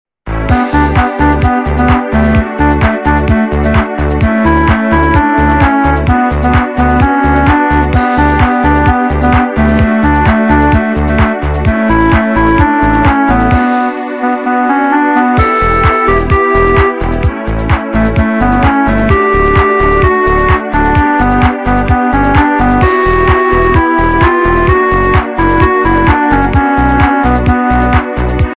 - западная эстрада
качество понижено и присутствуют гудки